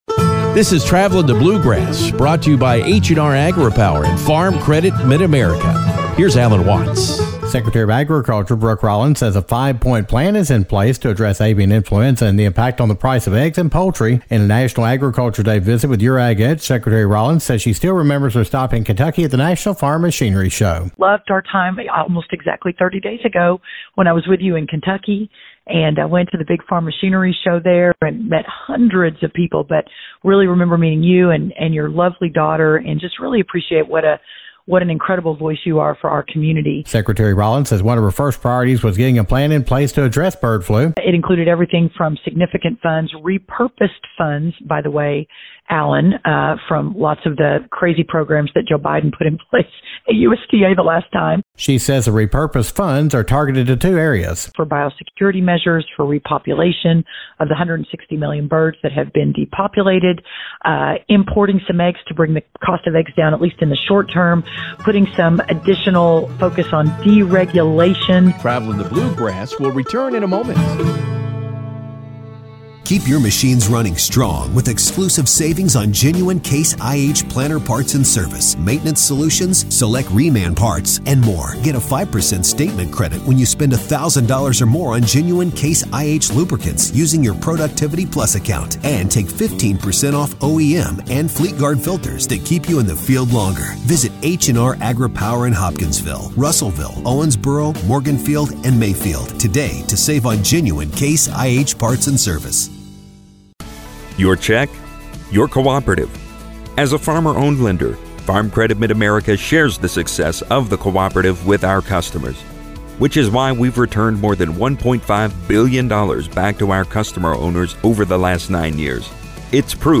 Secretary of Agriculture Brooke Rollins says the five point plan to reduce avian influenza is starting to work. Rollins talks about the plan, steps in the plan, and the ultimate impact that will have on the U.S. Economy.